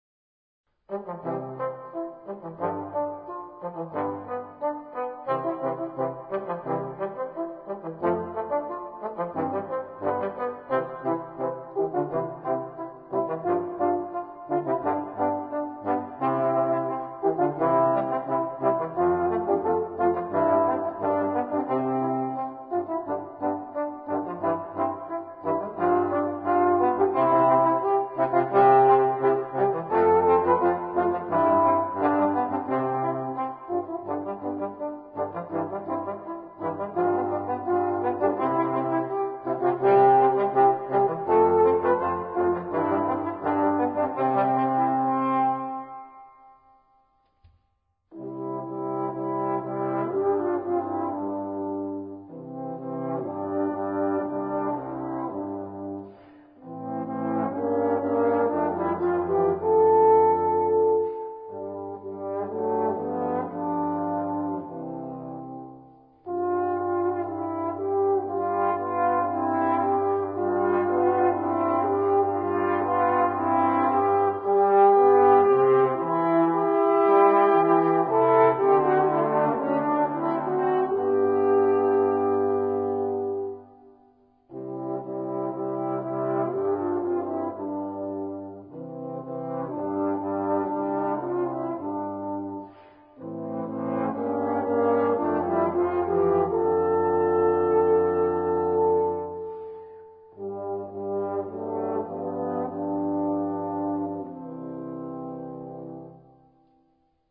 （トロンボーン3重奏）スコア（各パート譜付き●）売価（税込）￥2,934（本体価格\2,667.、消費税\267.)